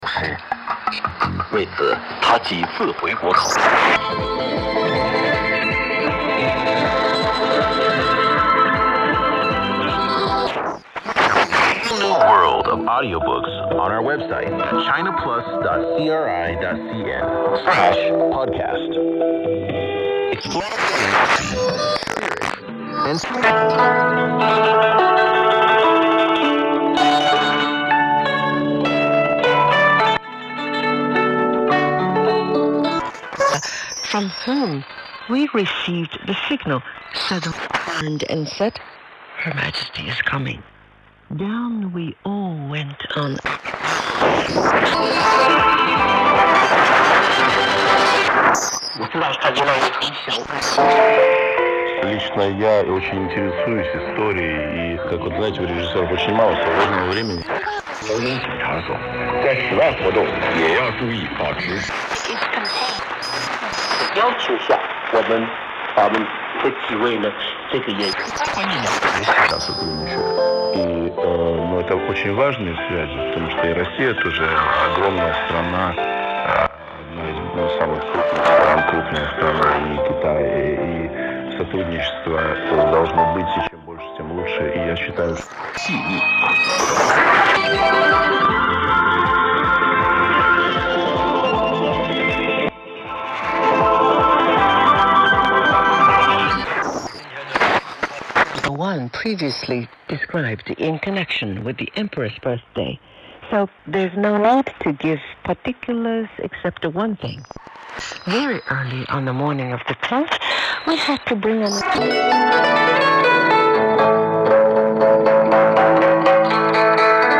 Что то не вставилась запись АМ сигнала.
Звучит качественно.